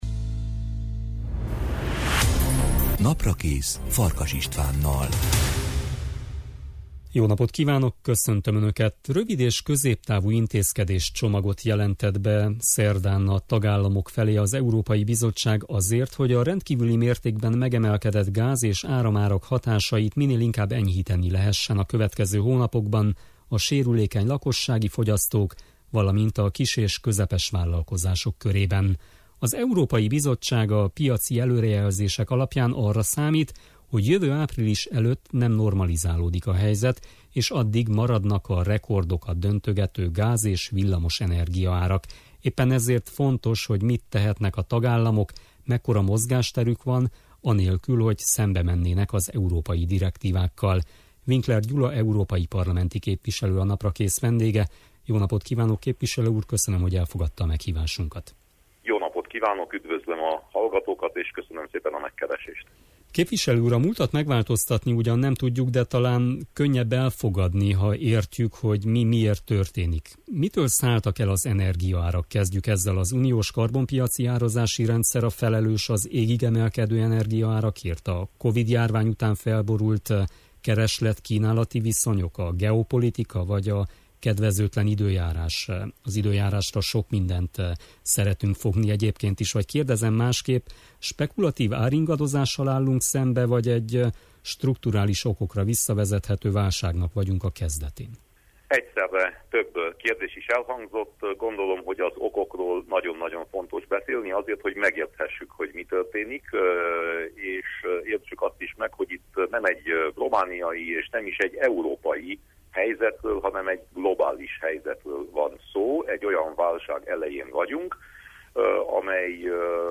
Winkler Gyula európai parlamenti képviselő a Naprakészben.